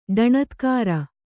♪ ḍaṇatkāra